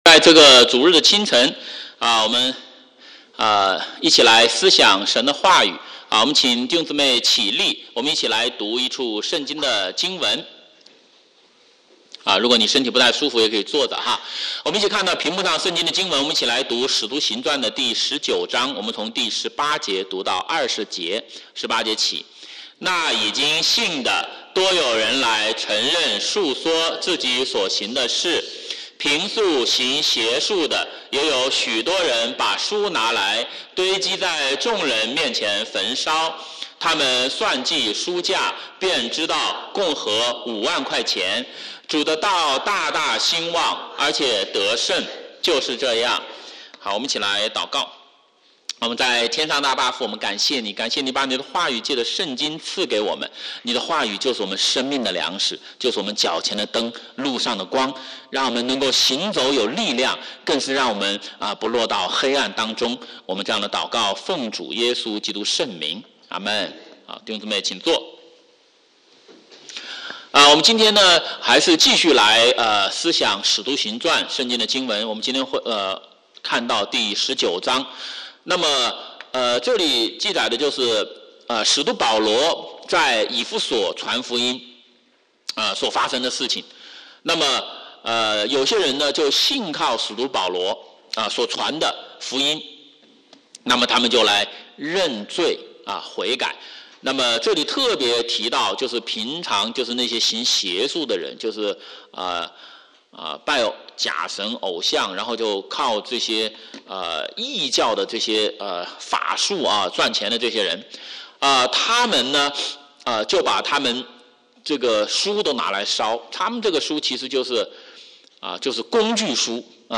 华语主日崇拜讲道录音